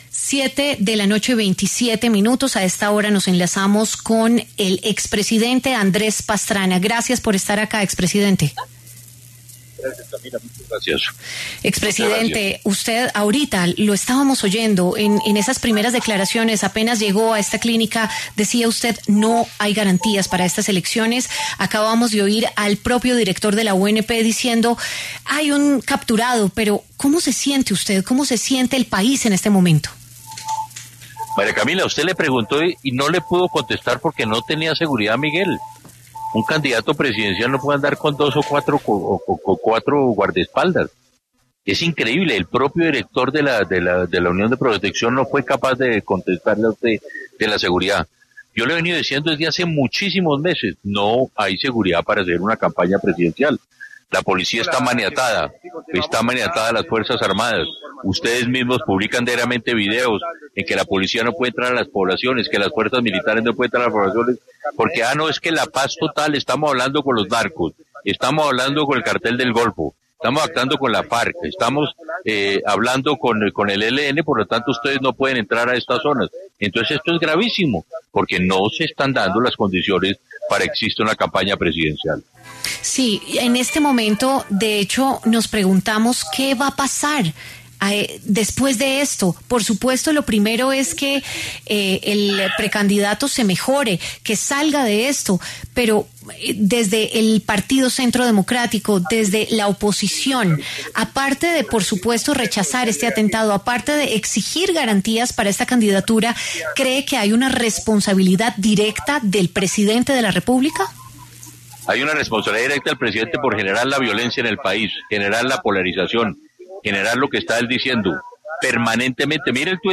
El expresidente Andrés Pastrana habló con W Radio sobre el atentado que sufrió el precandidato presidencial Miguel Uribe Turbay, del Centro Democrático. El exmandatario señaló al presidente Gustavo Petro por “incitar” a la violencia en Colombia.